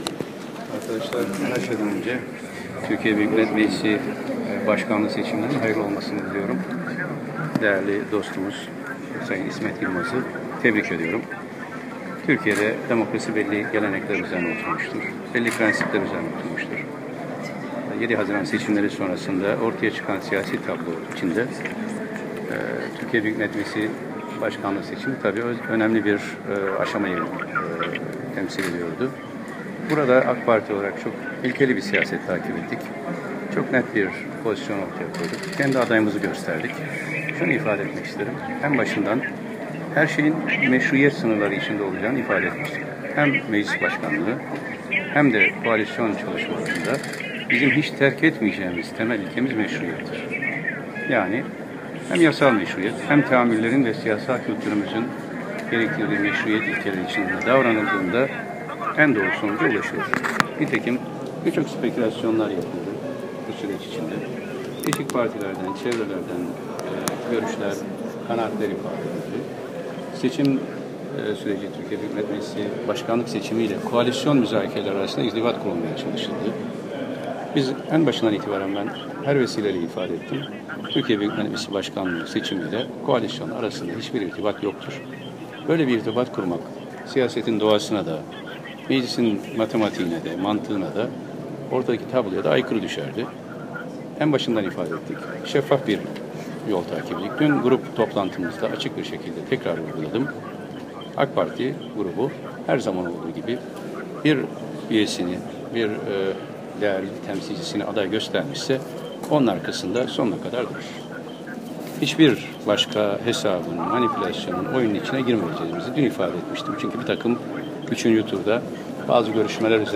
Ahmet Davutoğlu'nun Açıklaması